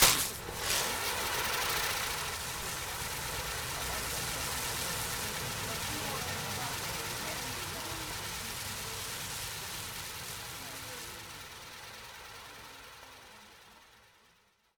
firework / spin.wav
spin.wav